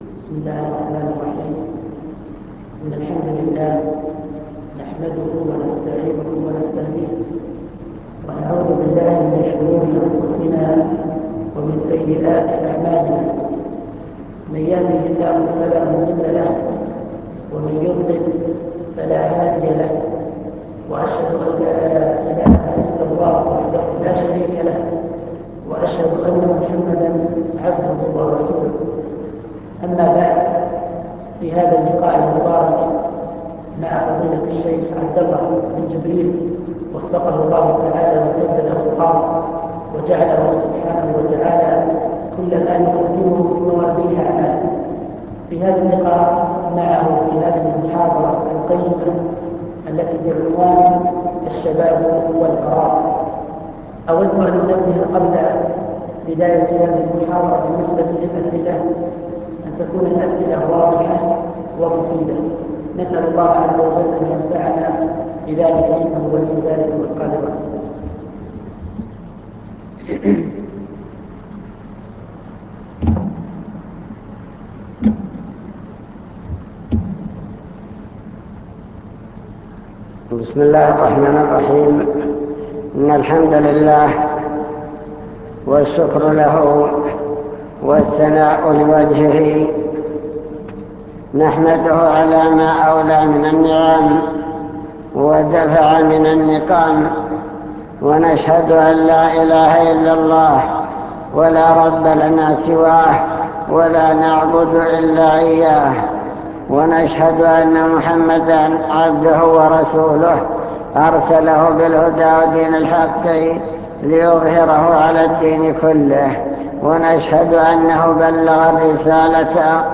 المكتبة الصوتية  تسجيلات - محاضرات ودروس  محاضرة بعنوان الشباب والفراغ